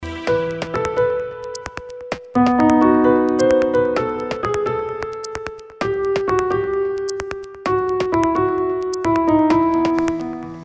آهنگ موبایل شاد و بی کلام